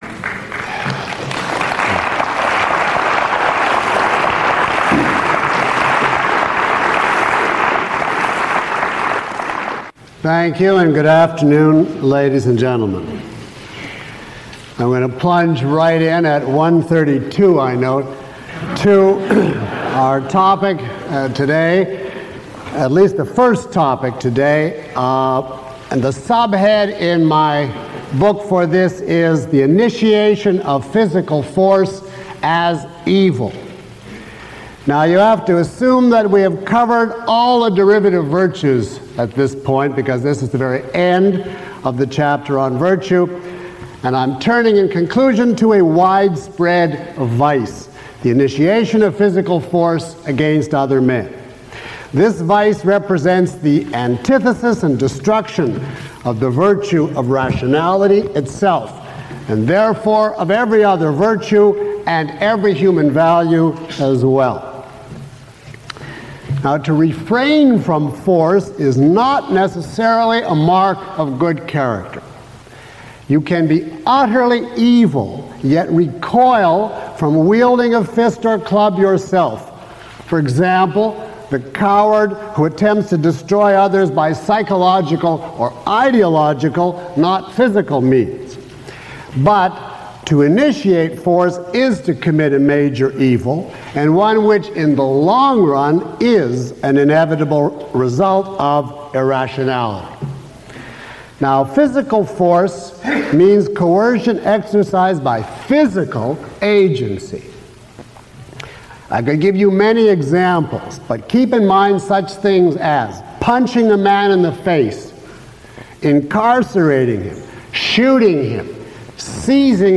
Lecture 02 - Moral Virtue.mp3